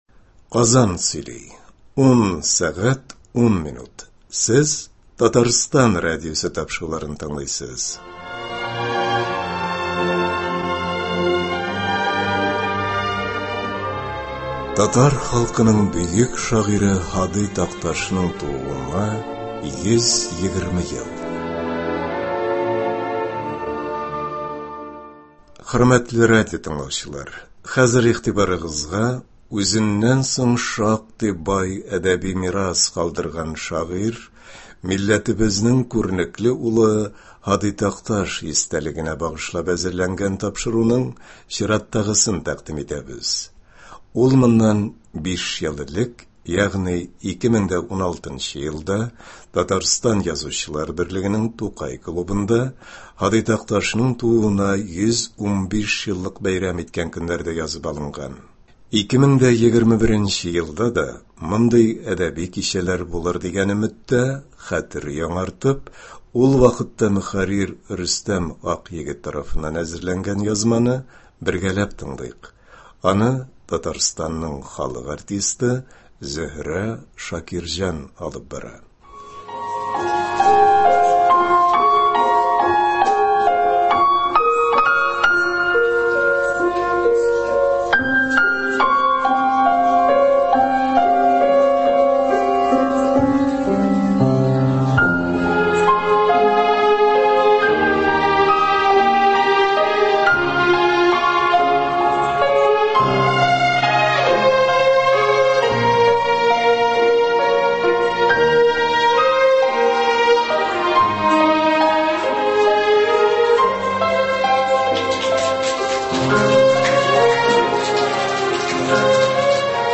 Ул моннан 5 ел элек, ягъни 2016 елда, Татарстан Язучылар берлегенең Тукай клубында, Һади Такташның тууына 115 еллыгын бәйрәм иткән көннәрдә язып алынган. 2021 елда да мондый әдәби кичәләр булыр дигән өметтә